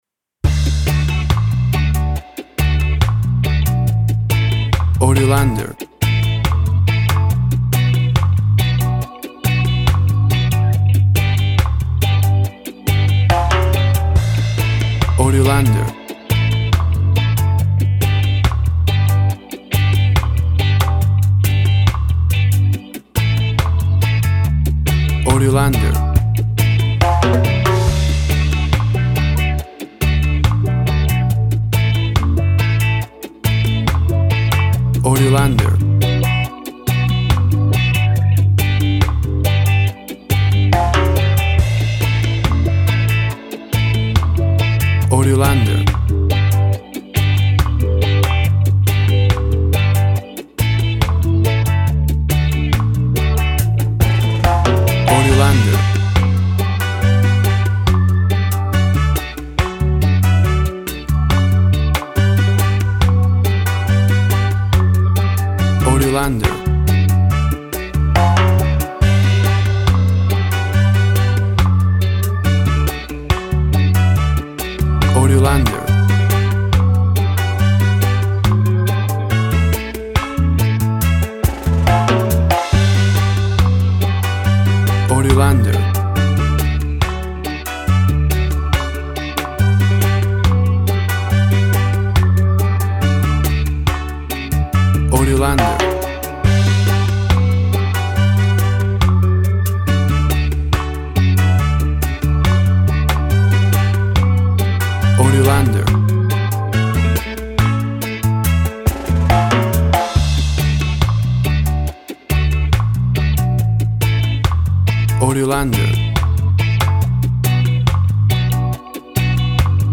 Classic reggae music with that skank bounce reggae feeling.
Tempo (BPM) 70